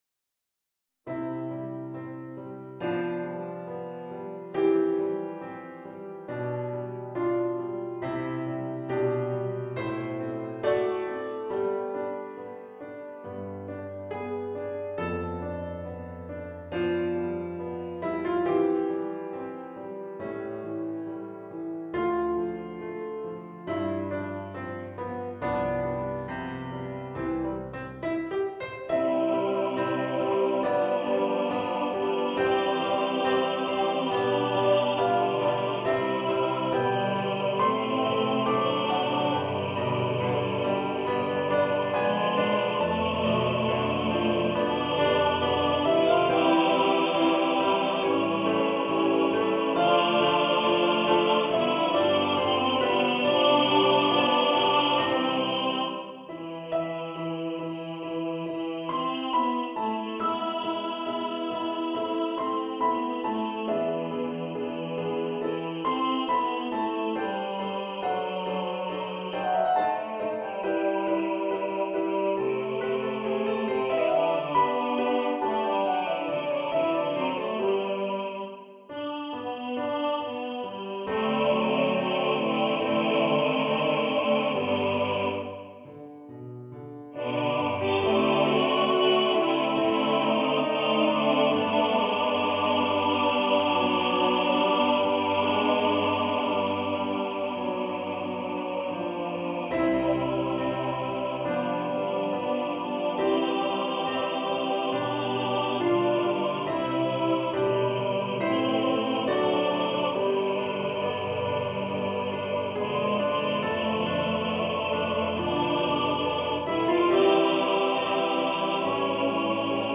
for TTBB
Choir (SATB or SA+Men or SSA or TTBB)
(Choir - Male voices)